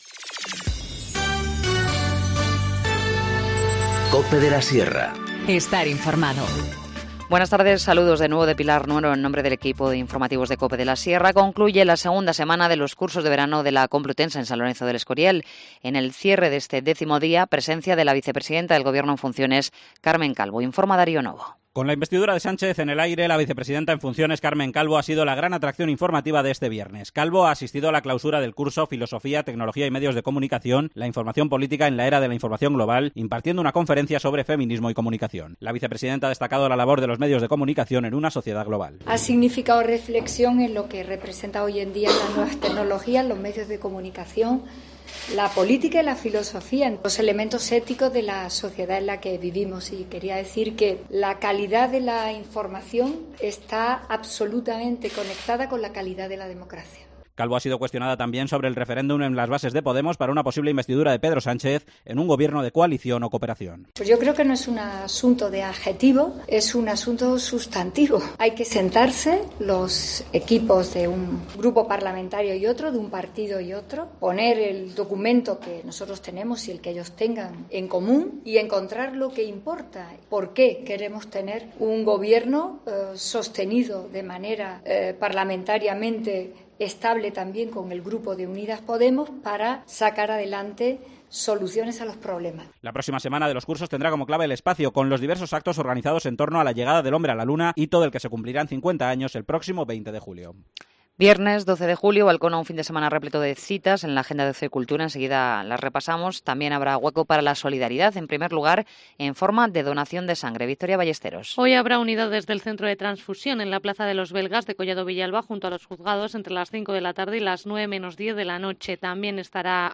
Informativo Mediodía 12 julio 14:50h